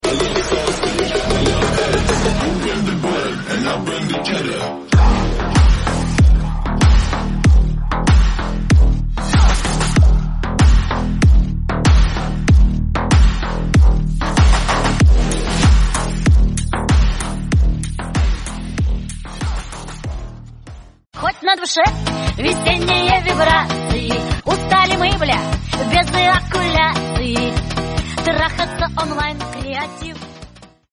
• Качество: 64, Stereo
мужской голос
жесткие
Electronic
басы
Bass House
забавный голос
Mashup